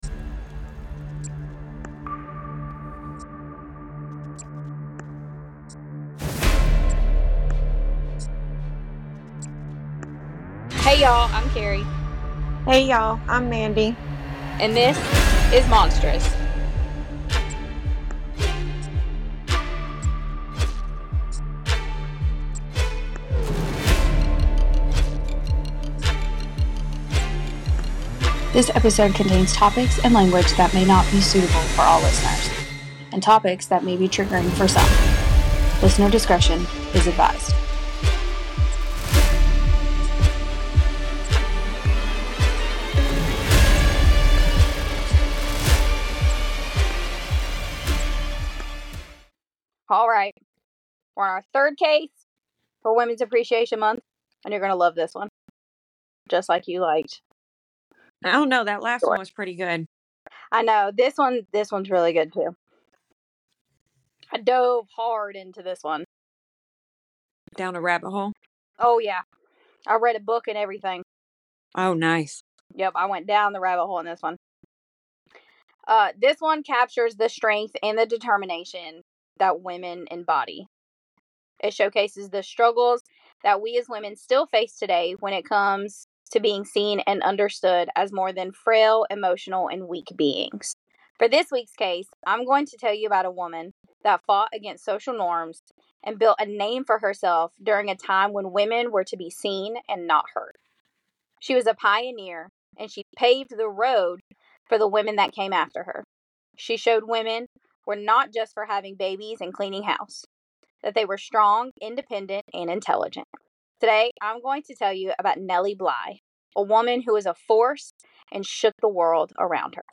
Monstrous is a true crime podcast with a dash of all things spooky, mystical and creepy hosted by two friends, one with a love of all things true crime, spine tingling and the world of the unknown and the other some would say has an obsession with true crime, a love for spooky but does not mess with the world of the dead. Join us every other Monday and every other Wednesday as we cover murders, serial killers, cold cases, mysteries, cults and all things monstrous.